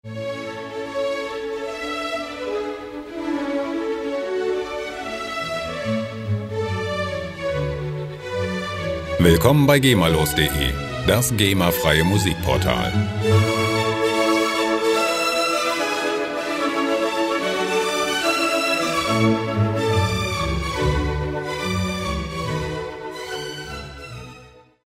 Gema-freie Klassik Loops
Musikstil: Klassik
Tempo: 144 bpm